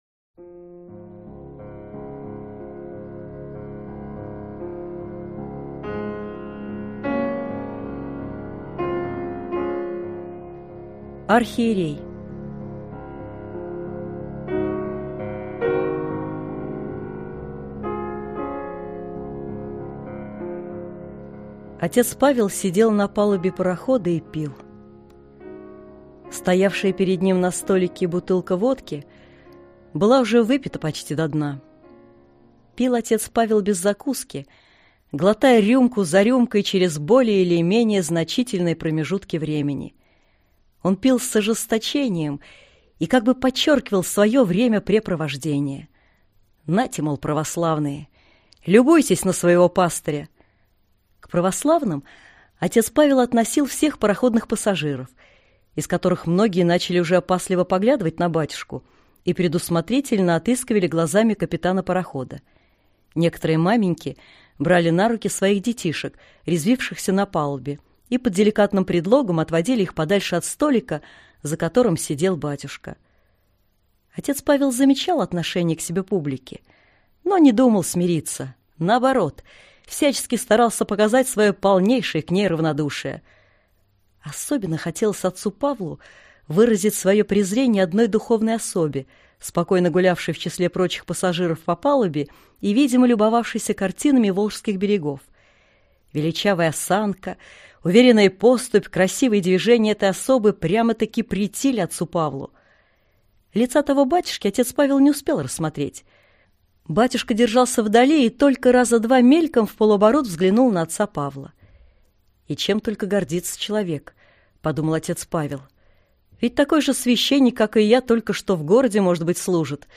Аудиокнига Архиерей | Библиотека аудиокниг